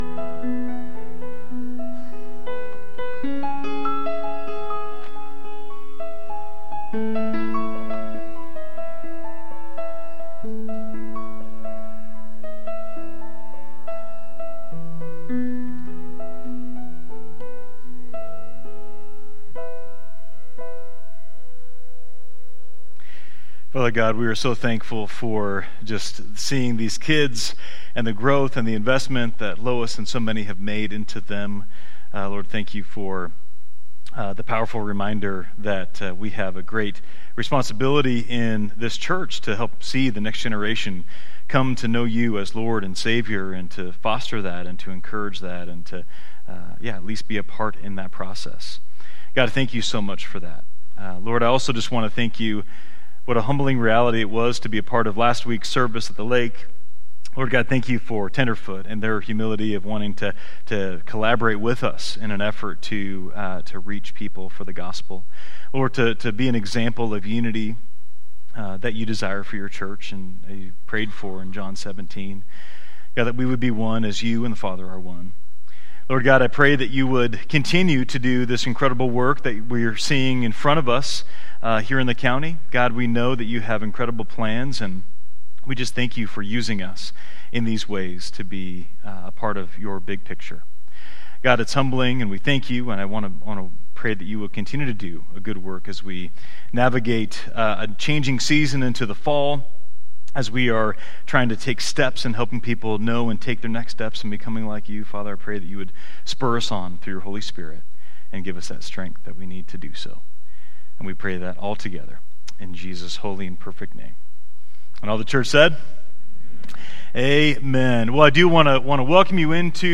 Download Download Reference Matthew 8:1-4 Sermon Notes 1.